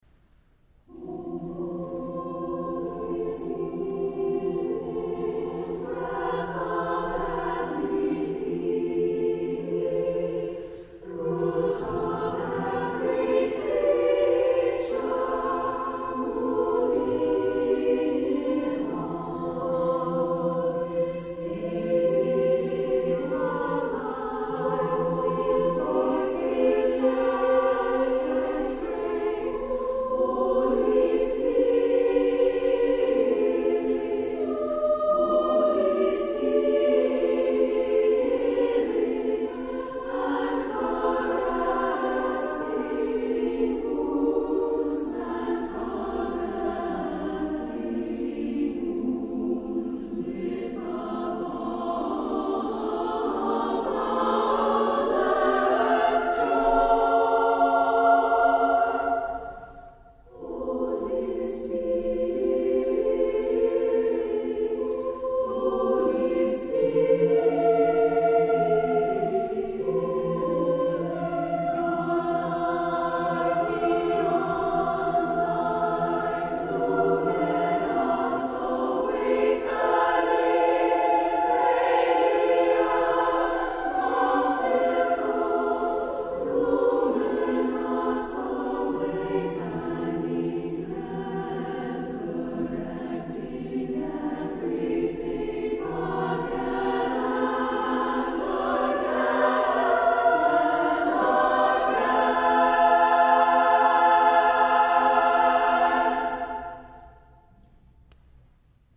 A bountiful and expansive setting
SSAA a cappella